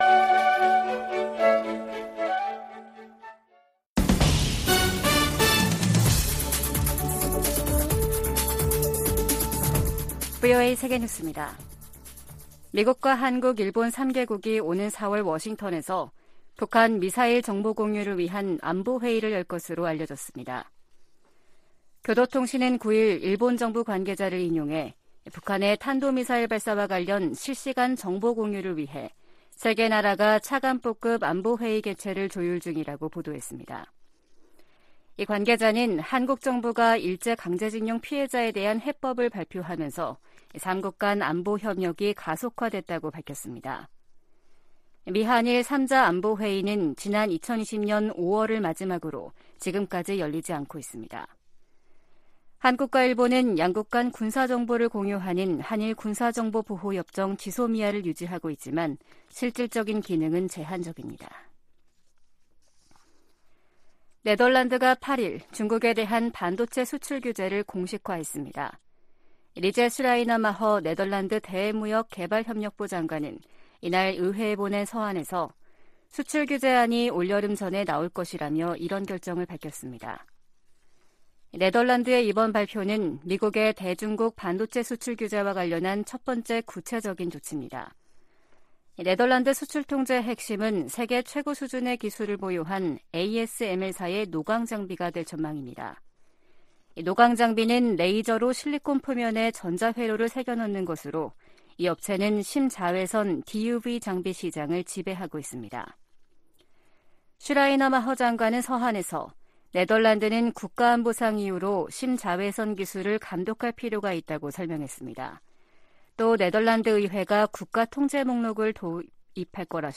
VOA 한국어 아침 뉴스 프로그램 '워싱턴 뉴스 광장' 2023년 3월 10일 방송입니다. 윤석열 한국 대통령이 오는 16일 일본을 방문해 기시다 후미오 총리와 정상회담을 갖는다고 한국 대통령실이 밝혔습니다. 미 국무부는 미한일 3자 확장억제협의체 창설론에 대한 입장을 묻는 질문에 두 동맹국과의 공약이 철통같다고 밝혔습니다. 권영세 한국 통일부 장관은 미래에 기초한 정책을 북한 지도부에 촉구했습니다.